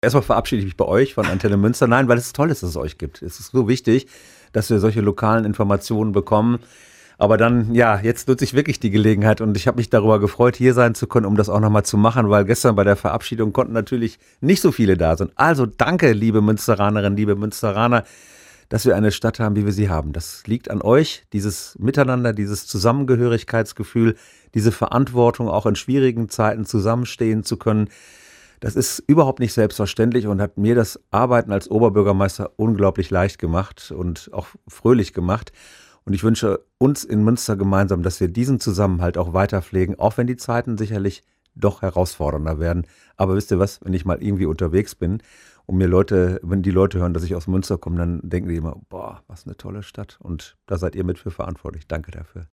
Die erste Amtshandlung an seinem letzten Arbeitstag war der Besuch bei ANTENNE MÜNSTER.